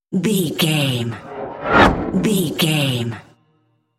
Whoosh fast bright
Sound Effects
Fast
futuristic
whoosh